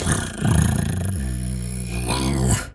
pgs/Assets/Audio/Animal_Impersonations/cat_2_purr_09.wav at master
cat_2_purr_09.wav